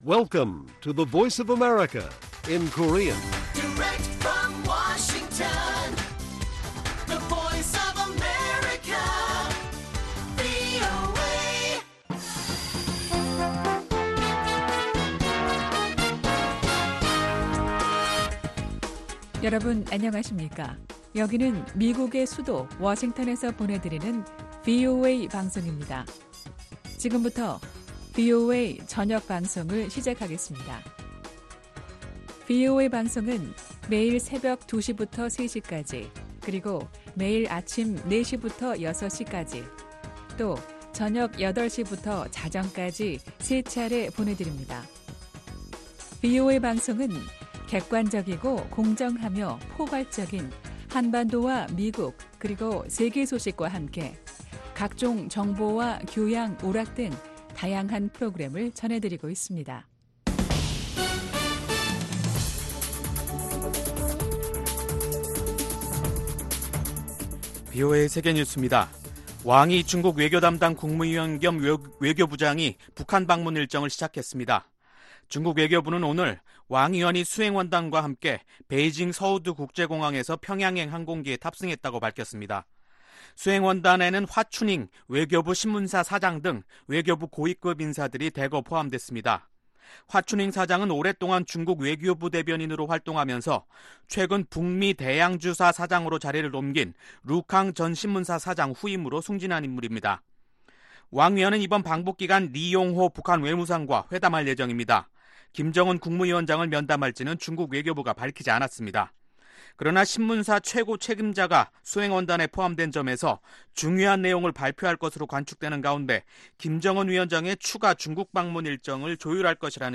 VOA 한국어 간판 뉴스 프로그램 '뉴스 투데이', 2019년 9월 2일 1부 방송입니다.